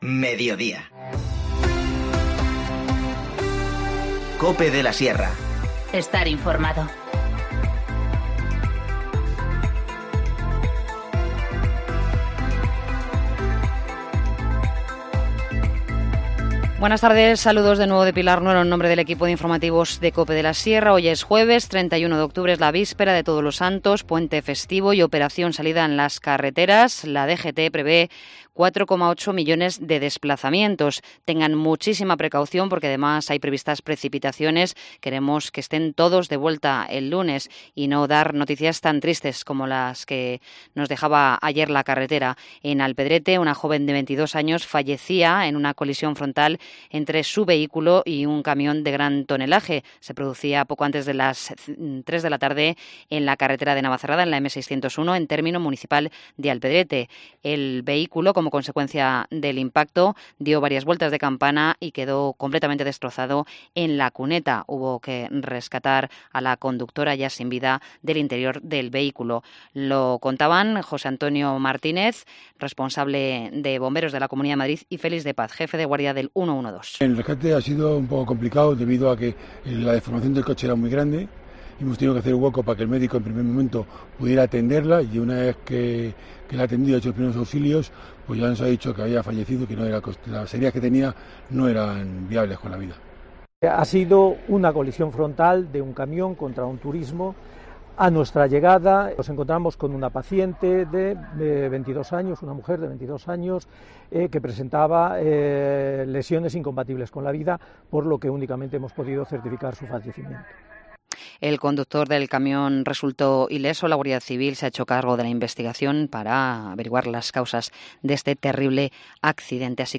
Informativo Mediodía 31 octubre 14:50h
INFORMACIÓN LOCAL